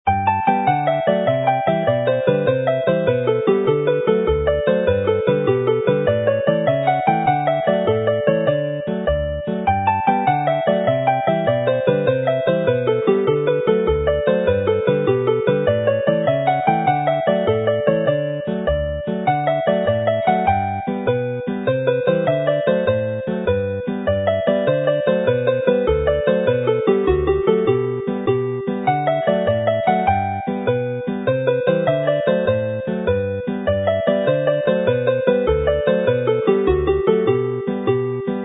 The set concludes with another lively jig